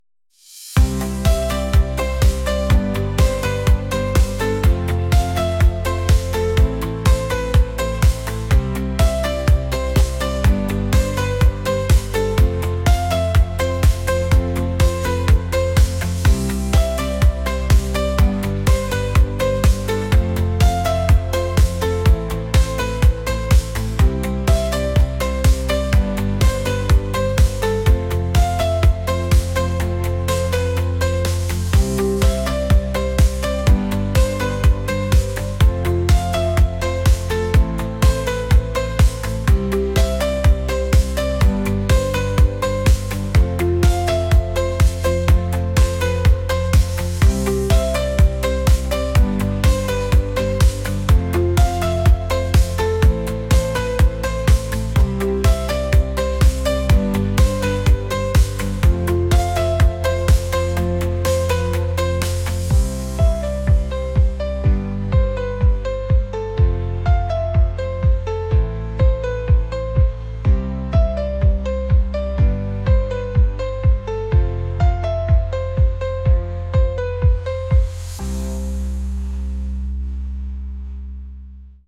pop | indie | folk